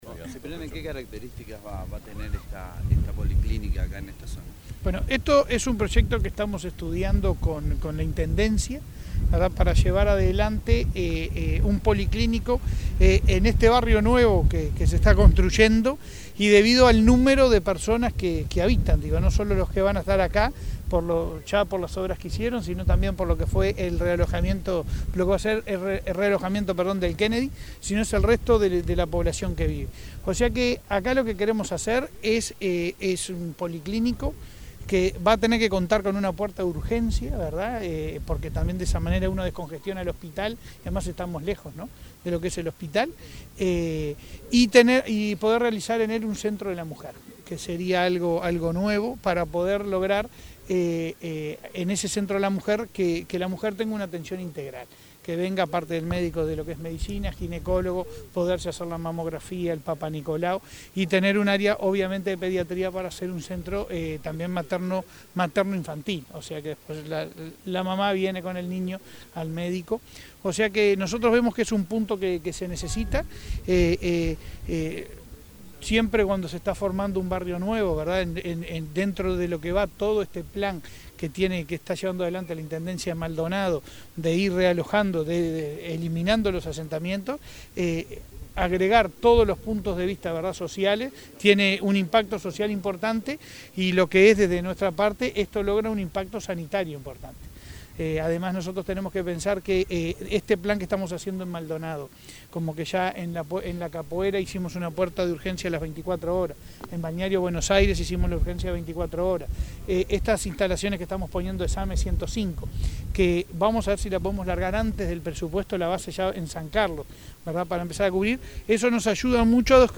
Declaraciones del presidente de ASSE, Leonardo Cipriani